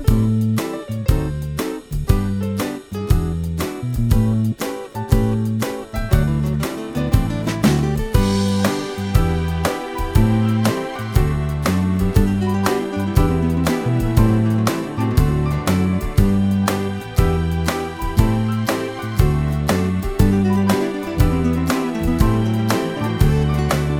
no Backing Vocals Comedy/Novelty 3:30 Buy £1.50